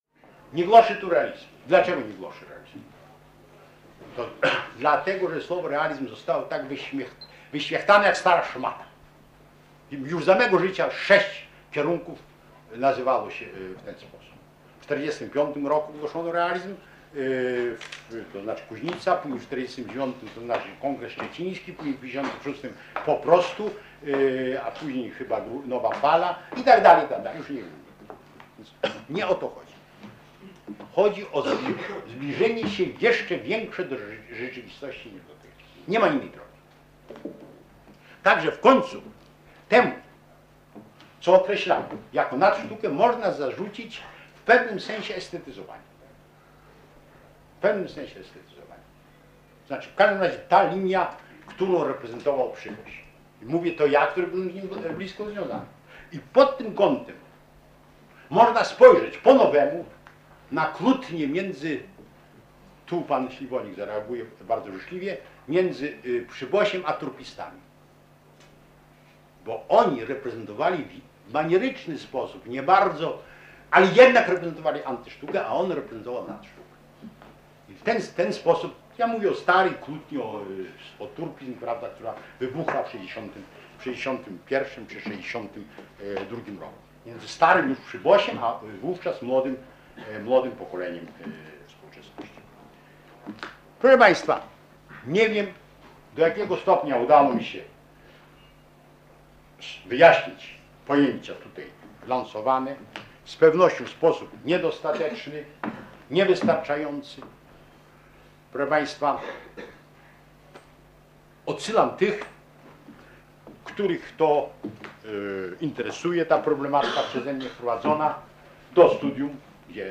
SPOTKANIA JESIENNE '81: relacja [dokument dźwiękowy] - Pomorska Biblioteka Cyfrowa
Dyskusja w KSW ŻAK nt. aktualnego stanu literatury polskiej